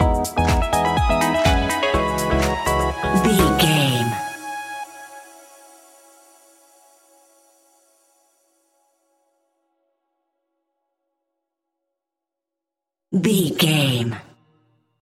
Aeolian/Minor
G#
groovy
uplifting
energetic
bouncy
synthesiser
drum machine
electro house
synth bass